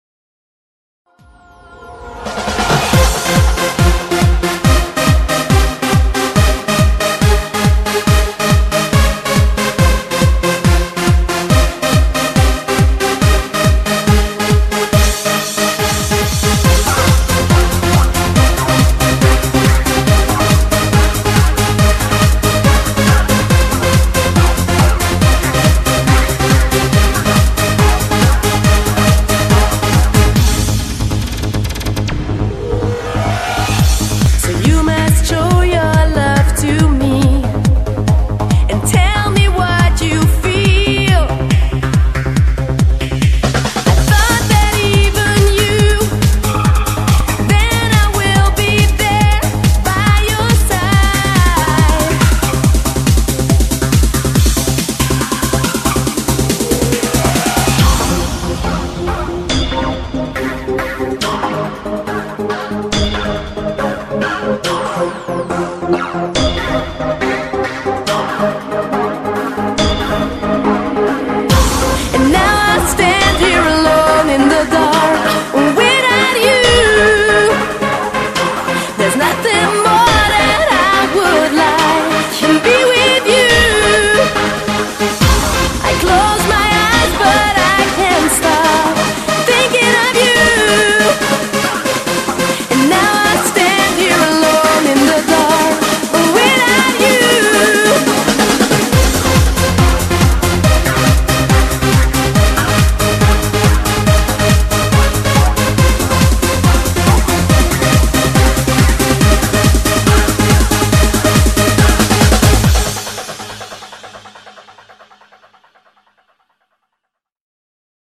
BPM140
Audio QualityLine Out